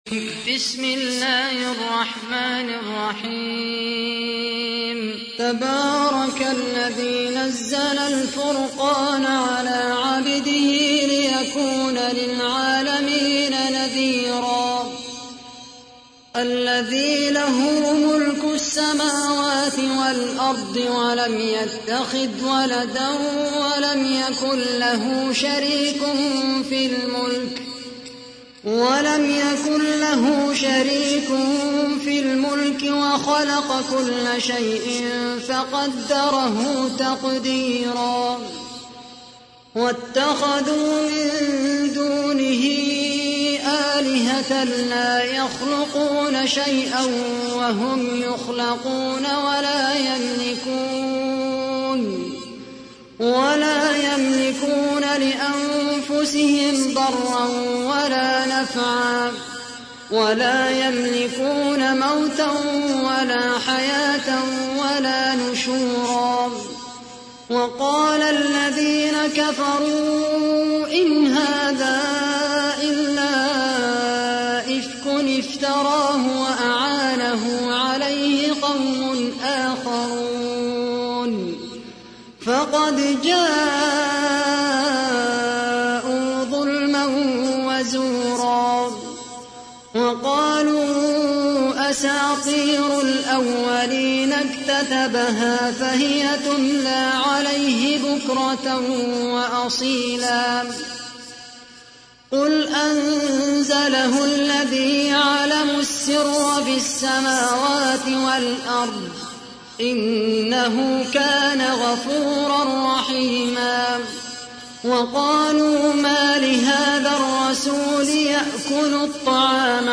تحميل : 25. سورة الفرقان / القارئ خالد القحطاني / القرآن الكريم / موقع يا حسين